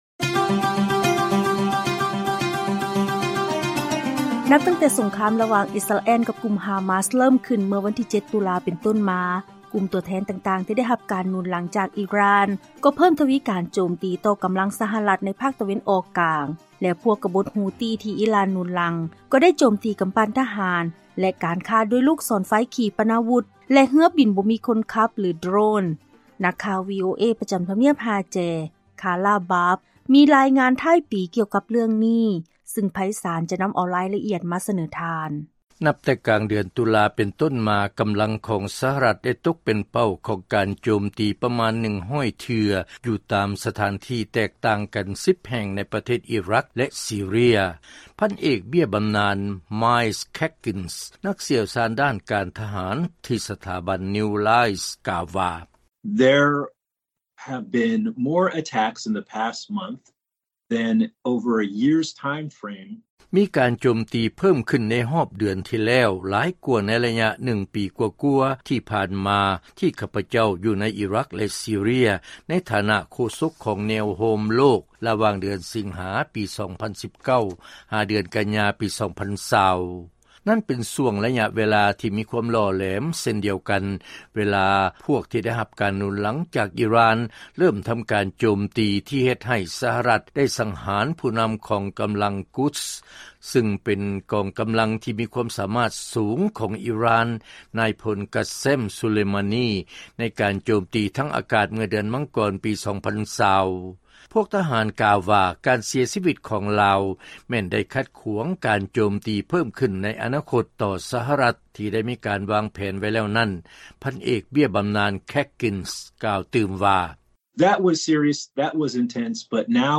ເຊີນຟັງລາຍງານ ກຳລັງສະຫະລັດ ໃນພາກຕາເວັນອອກກາງ ຖືກໂຈມຕີເພີ້ມຂຶ້ນ ນັບແຕ່ເກີດສົງຄາມ ລະຫວ່າງອິສຣາແອລ ກັບກຸ່ມຮາມາສ